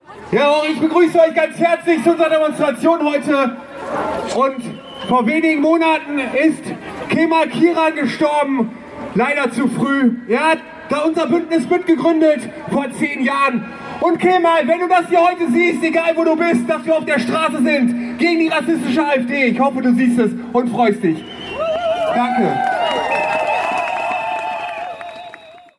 Düsseldorf stellt sich quer: Demonstration „Gegen die AfD und die Rechtsentwicklung der Gesellschaft“ (Audio 3/16)